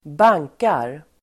Uttal: [²b'ang:kar]